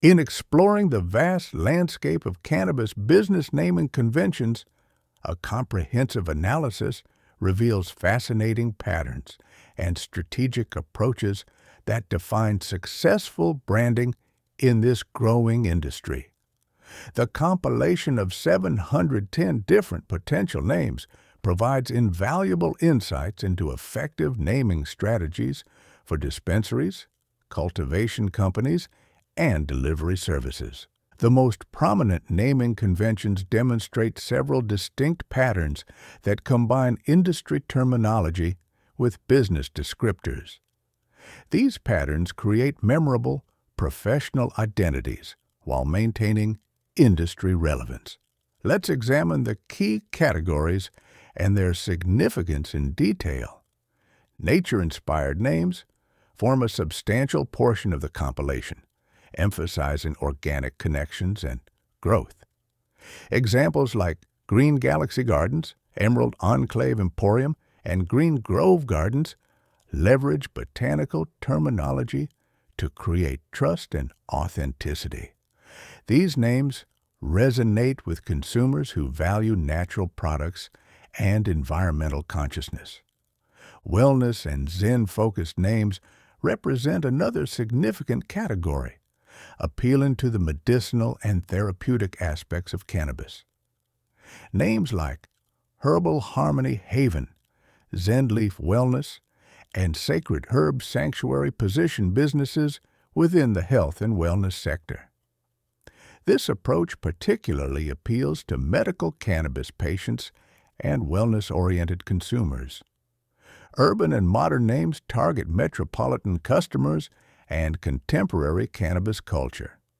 Featuring interviews with brand strategists, marketing experts, and dispensary owners, we'll uncover the stories behind some of the most memorable and innovative names in the industry.